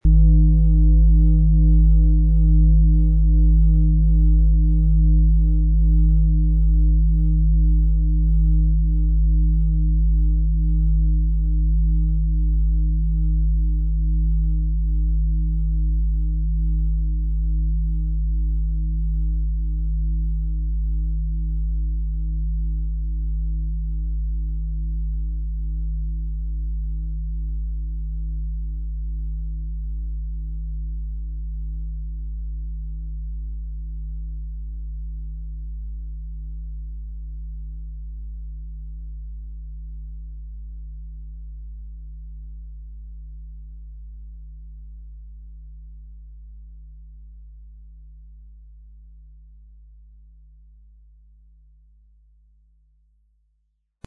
XXXL Planeten-Fußreflexzonen-Klangschale mit Wasserstoffgamma
Der Klang der universellen Harmonie.
Wohltuende Klänge bekommen Sie aus dieser Schale, wenn Sie sie mit dem kostenlosen Klöppel sanft anspielen.
MaterialBronze